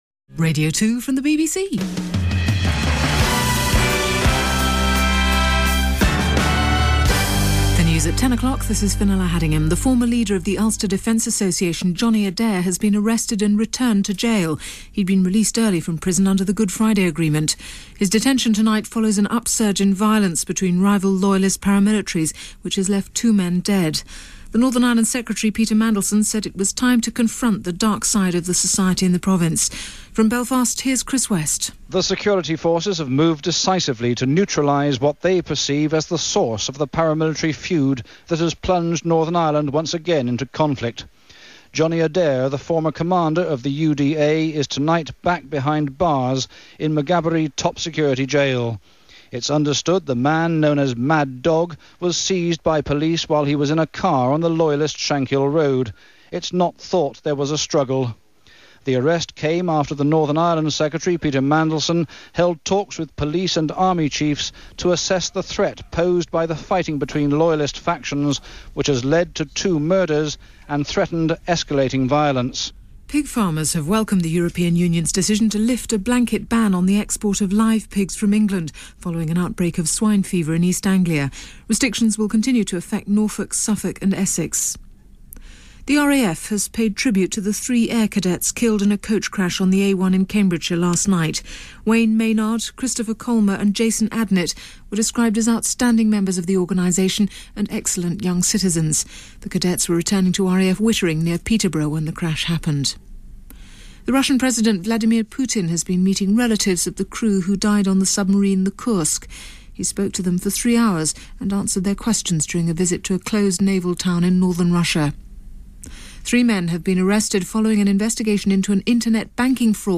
which is very easy to do as broken as my old Frontpage editor software is... after the news and a BBC Radio 2 station ID we get right to the program.